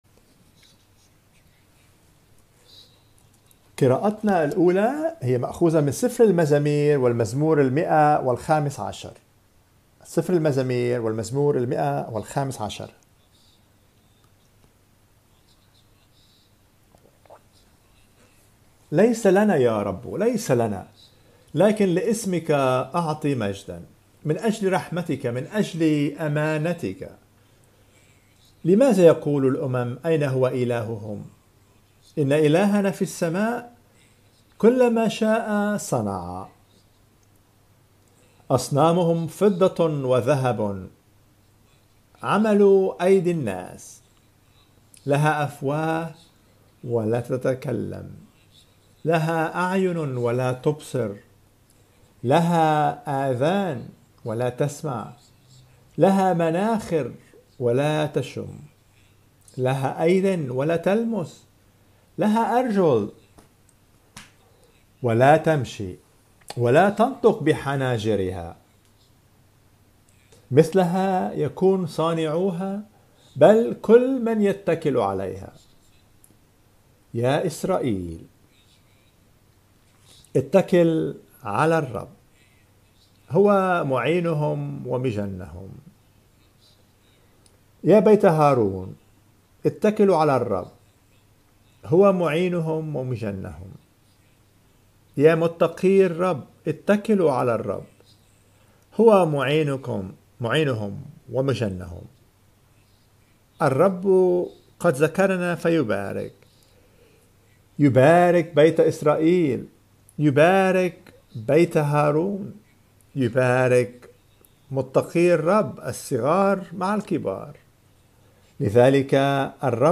Sermons by Scripture, Series and Topics preached at the Metropolitan Tabernacle in London.